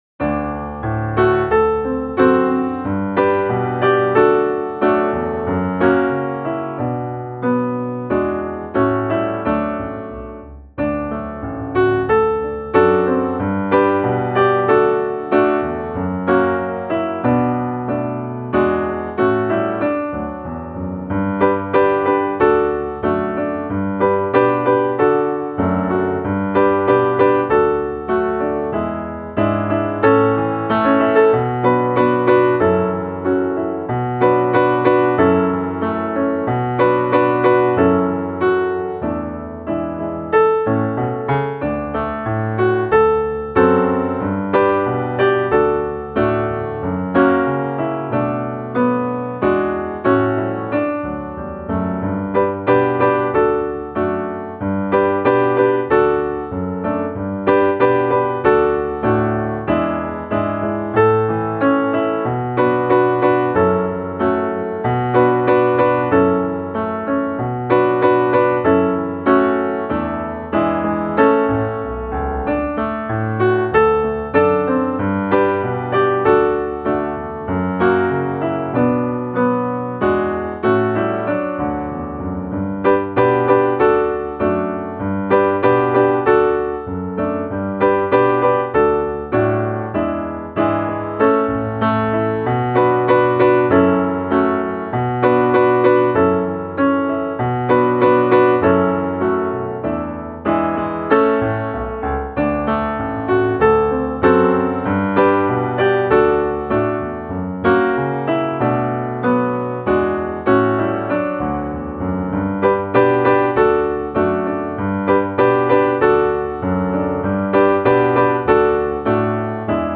Himlar och rymder - musikbakgrund